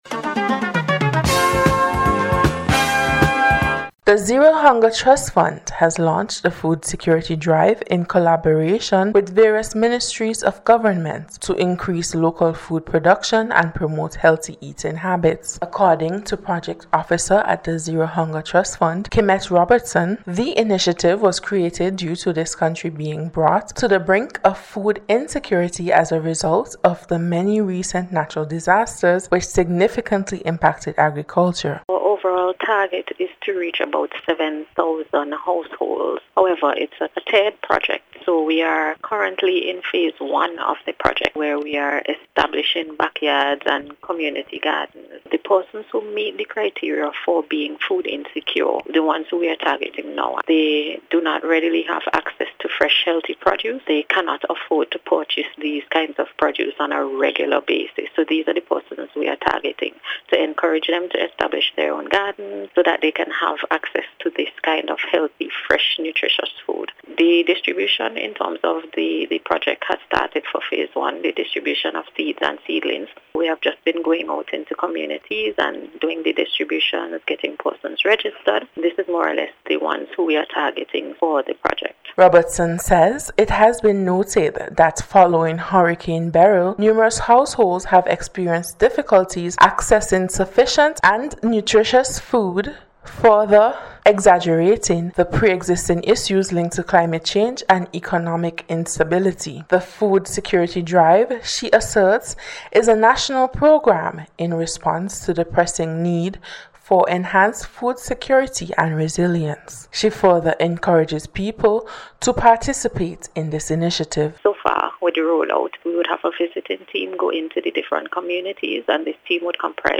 ZERO-HUNGER-FOOD-GARDEN-REPORT.mp3